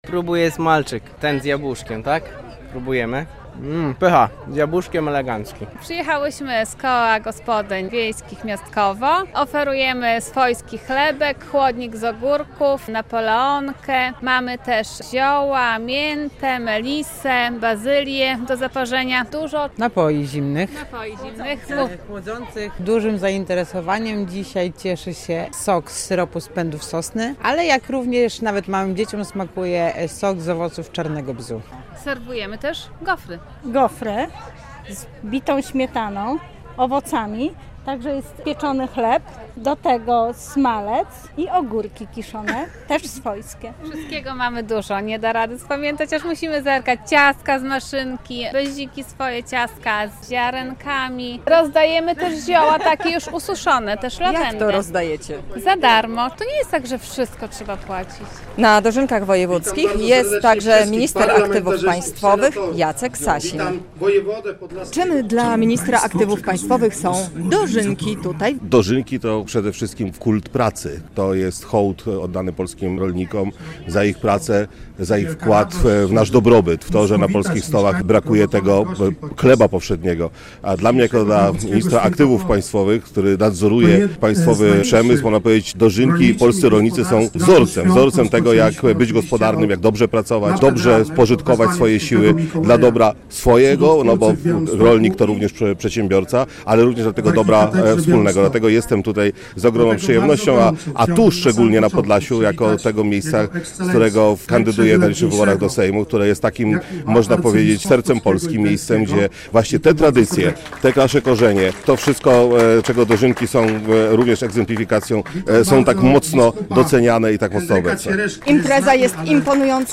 W Podlaskim Muzeum Kultury Ludowej mieszkańcy regionu świętują Dożynki Wojewódzkie - relacja